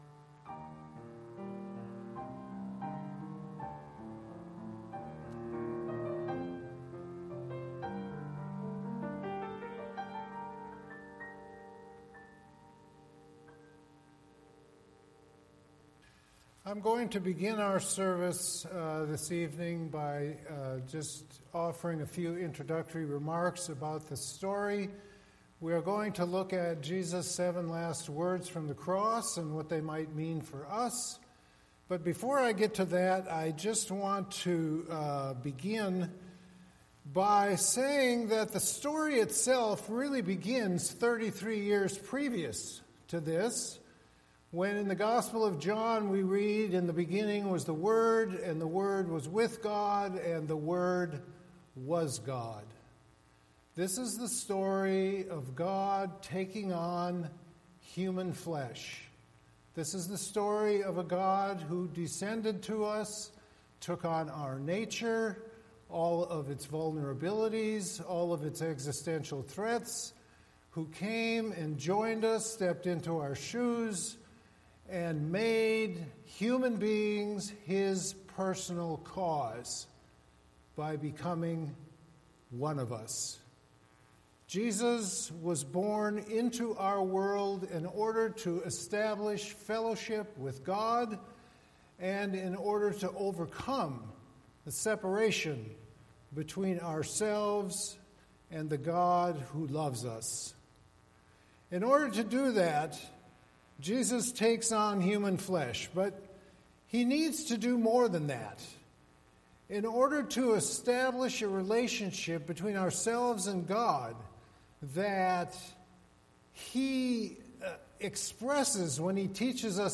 Recent Sermons
Good Friday